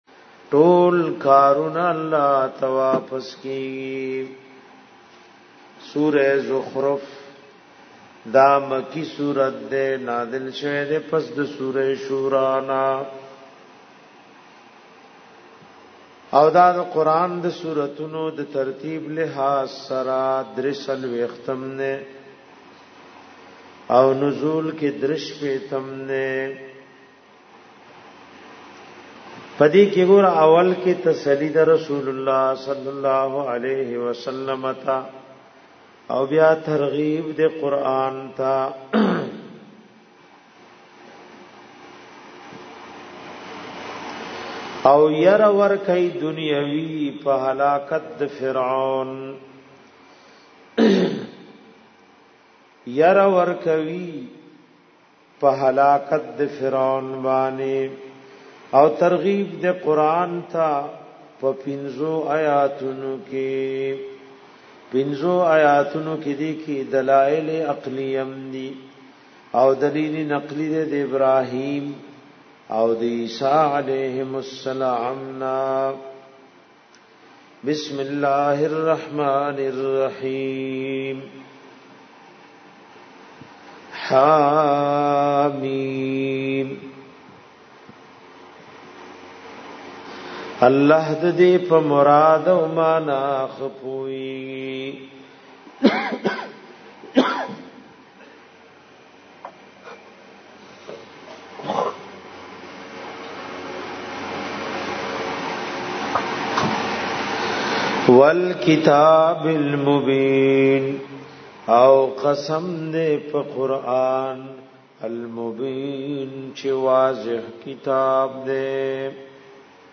dorae e tafseer